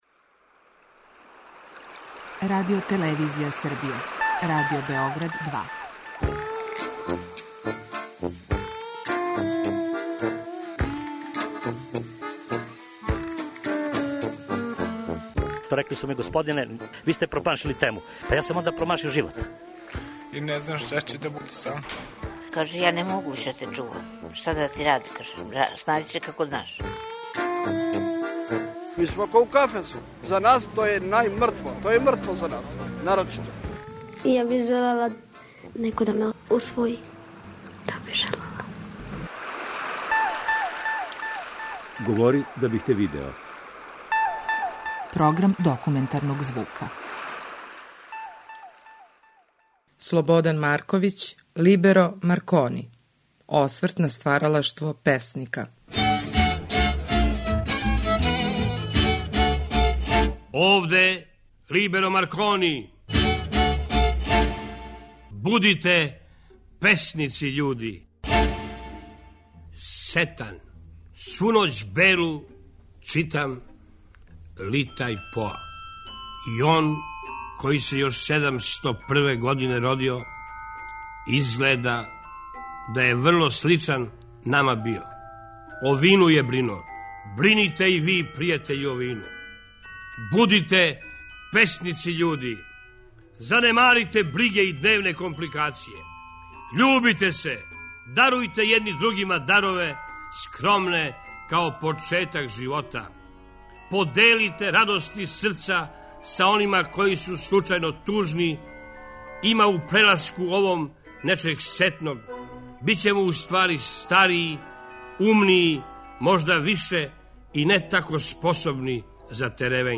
Документарни програм
Стихове је говорио сам песник.